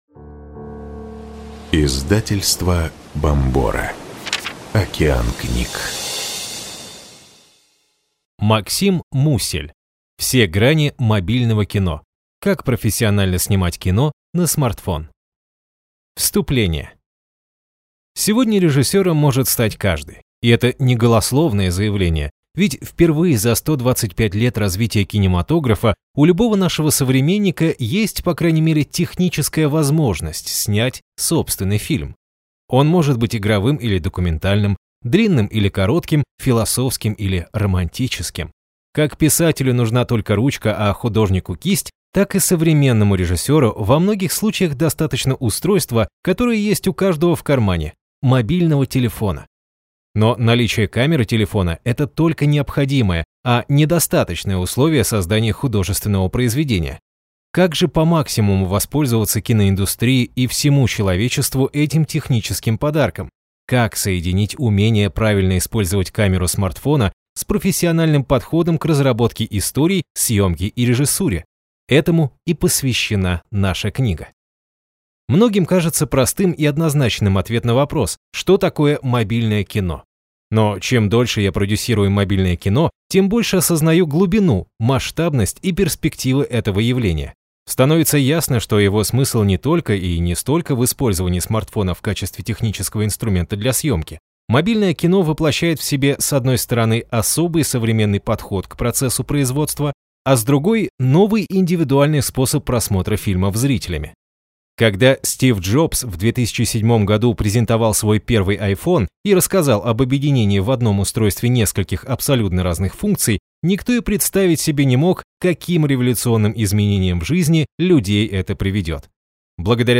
Аудиокнига Все грани мобильного кино. Как профессионально снимать кино на смартфон | Библиотека аудиокниг